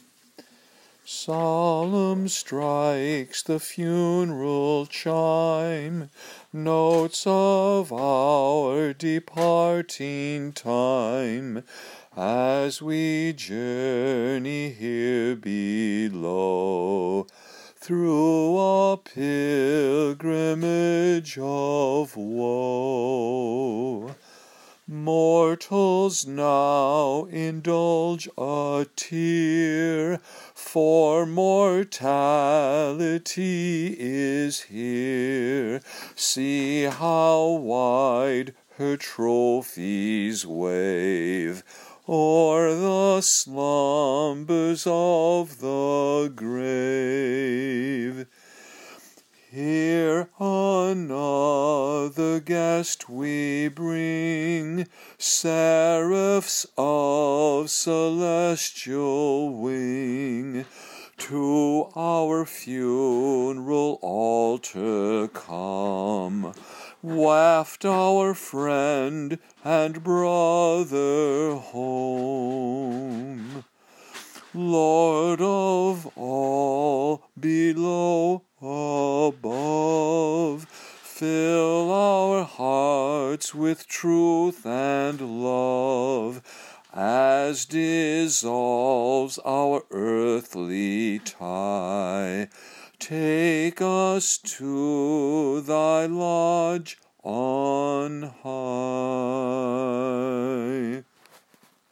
Click here to hear or download the Masonic Dirge words sung to Pleyel’s Hymn tune
(Funeral Dirge for MM Degree)